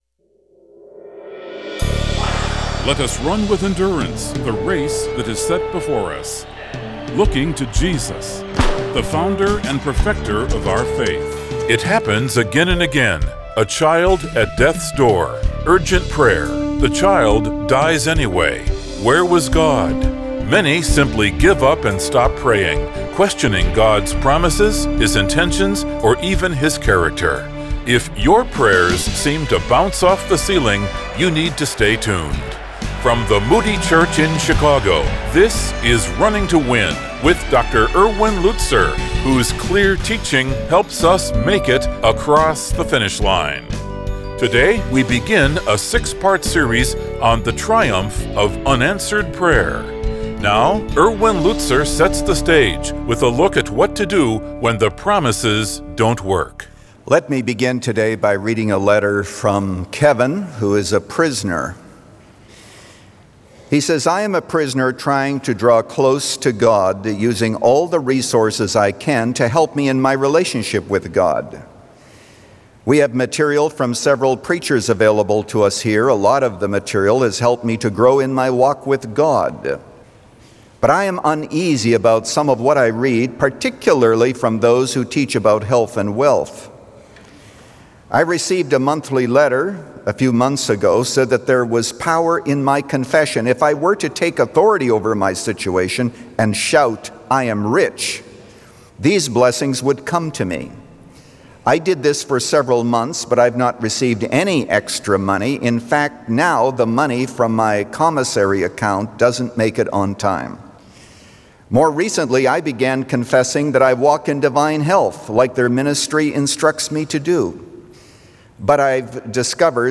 Today this program broadcasts internationally in six languages.